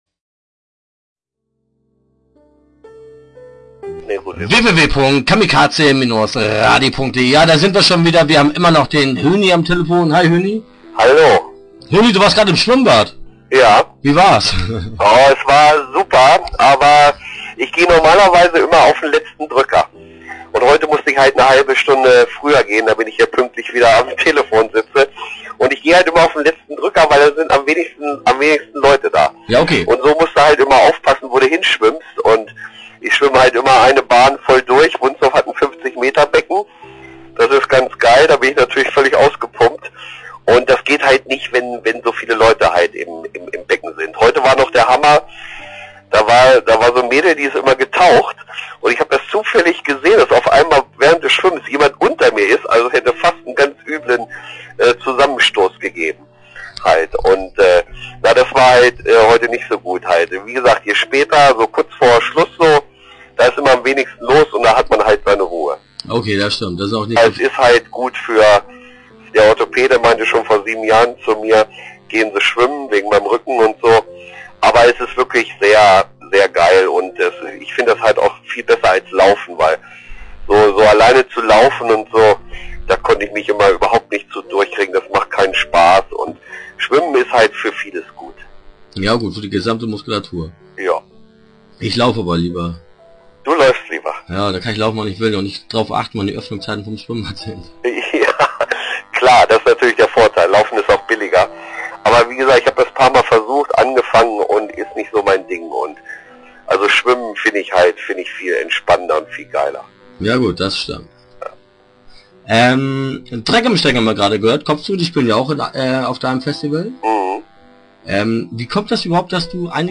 Start » Interviews » Punk am Ring 2008